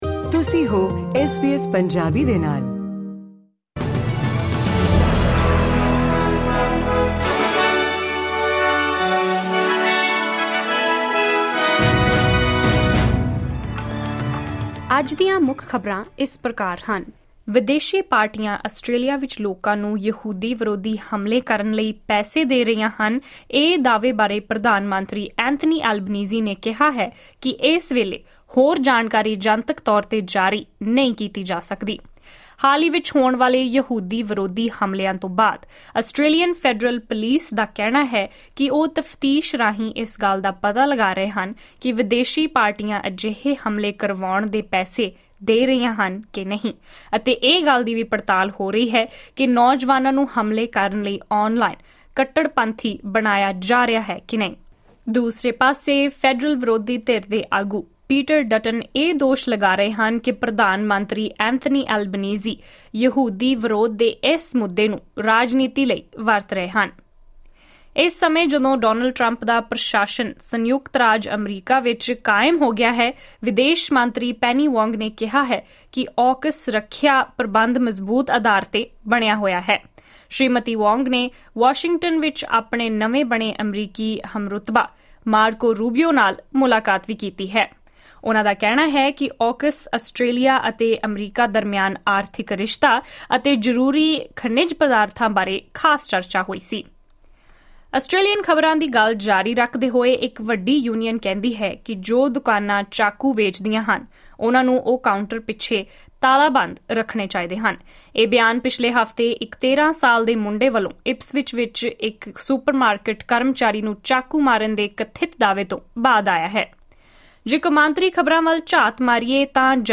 ਖ਼ਬਰਨਾਮਾ: ਵਿਦੇਸ਼ ਮੰਤਰੀ ਪੈਨੀ ਵੋਂਗ ਨੇ ਕੀਤੀ ਨਵੇਂ ਬਣੇ ਅਮਰੀਕੀ ਹਮਰੁਤਬਾ ਮਾਰਕੋ ਰੂਬੀਓ ਨਾਲ ਖਾਸ ਗੱਲਬਾਤ